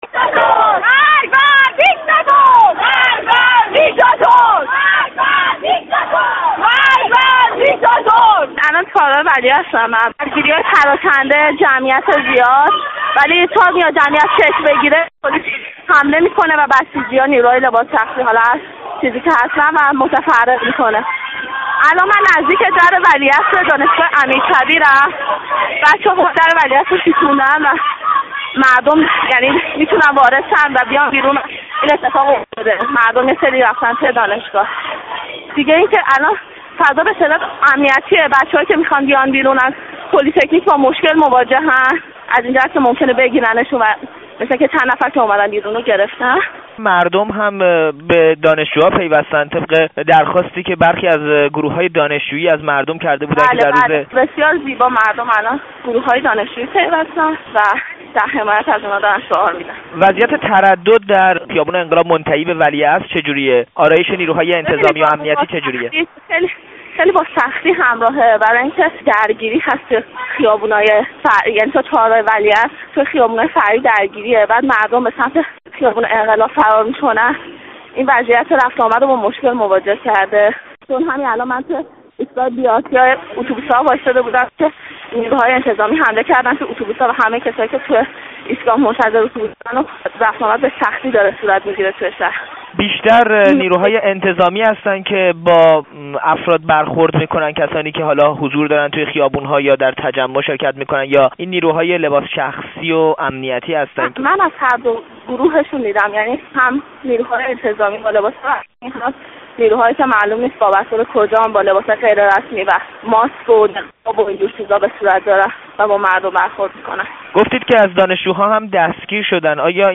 یک شاهد عینی دیگر از درگیری در خیابان ولی‌عصر و شکسته شدن در دانشگاه امیرکبیر و ورود مردم به این دانشگاه می‌گوید